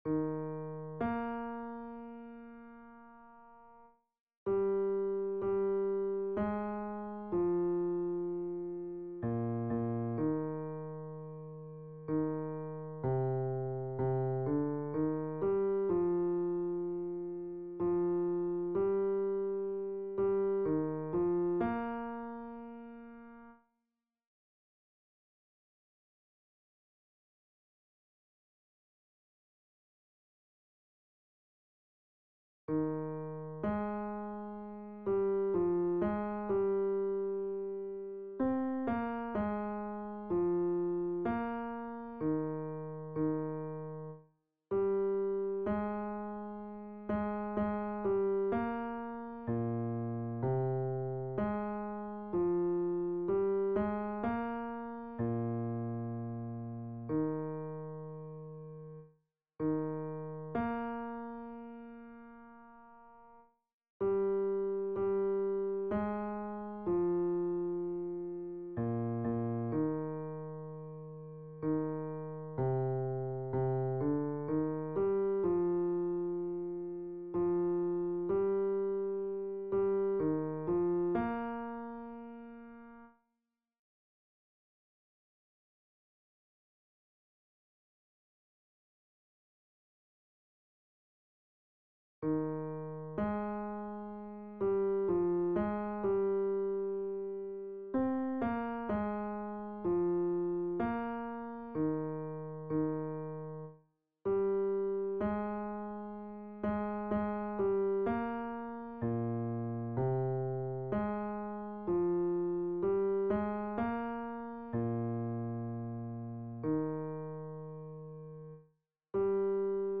Hymne à la nuit Basse - Chorale Concordia 1850 Saverne
Hymne-à-la-nuit-Basse.mp3